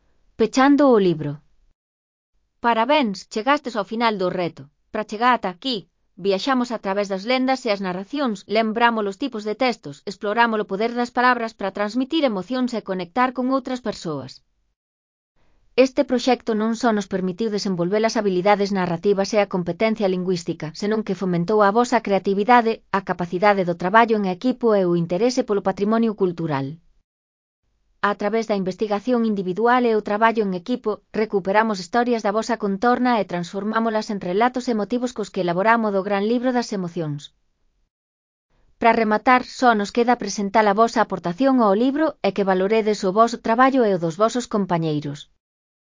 Elaboración propia (proxecto cREAgal) con apoio de IA voz sintética xerada co modelo Celtia.